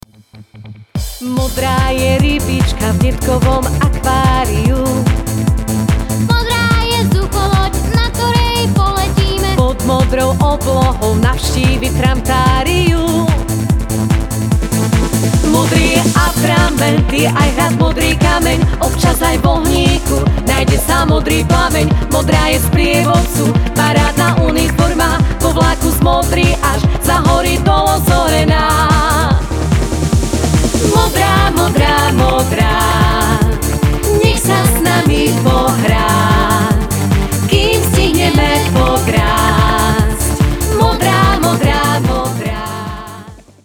klarinet,saxofon,altová flauta
gitara
detský spev
deti zo ZUŠ v Malackách – zbor